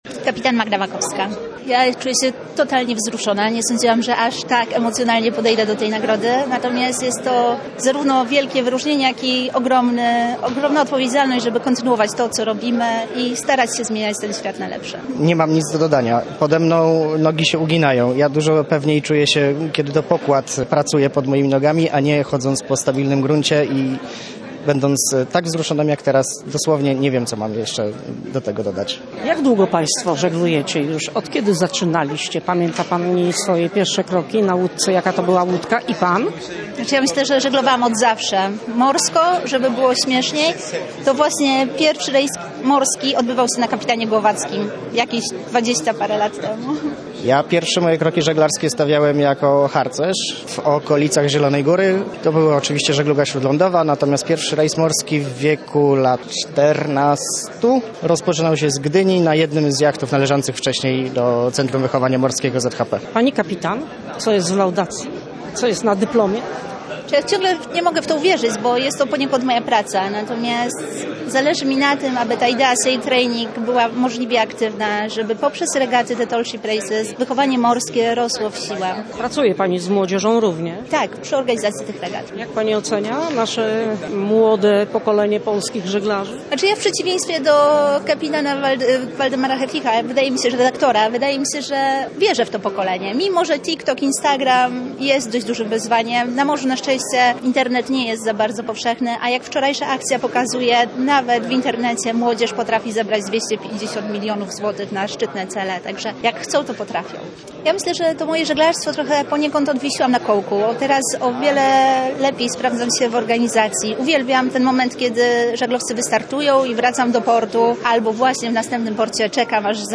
Nagrody_Wiktorowicza_rozmowa_z_laureatami_i_kapitula.mp3